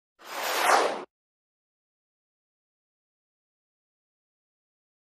SynthSpaceshipByPn PE207201
Synth Spaceship By 3; Fast, With Action Panning From Left To Right.